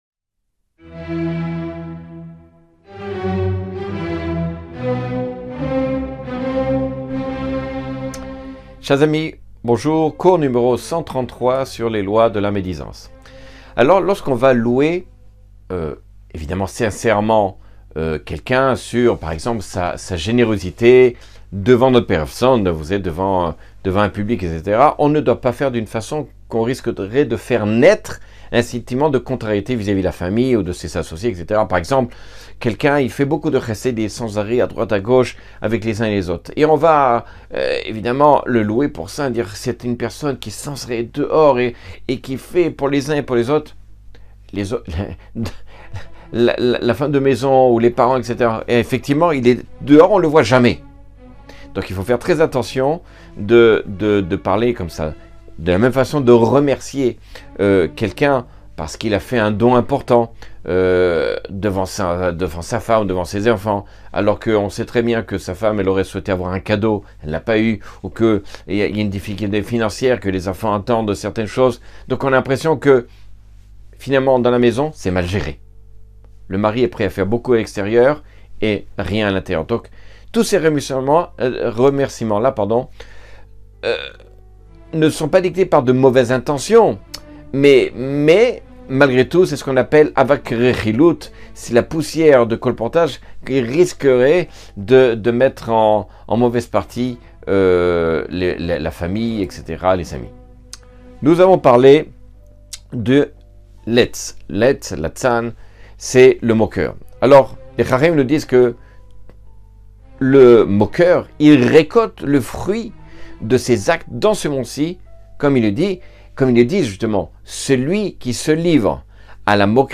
Cours 133 sur les lois du lashon hara.